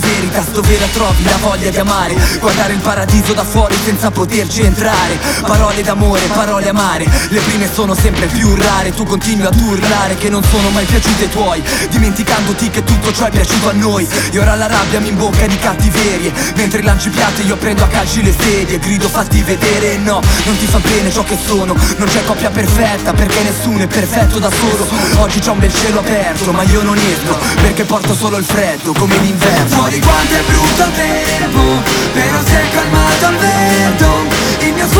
Жанр: Хип-Хоп / Рэп / Рок